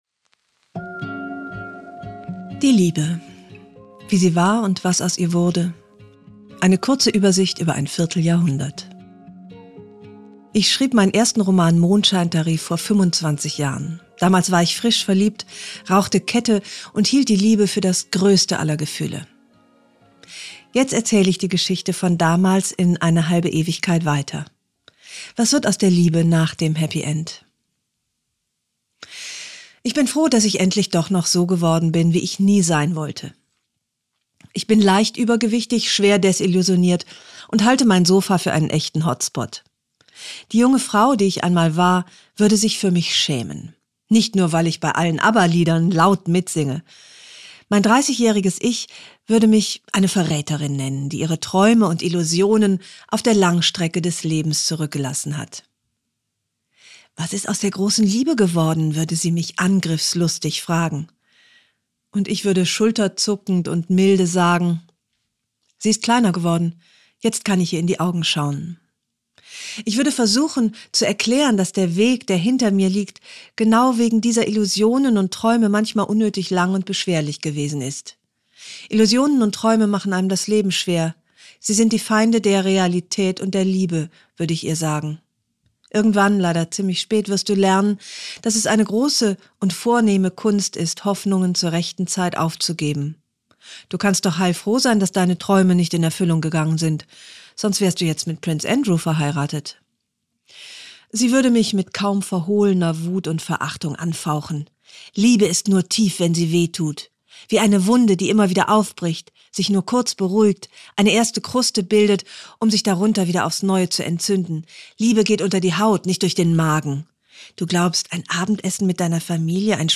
Audioartikel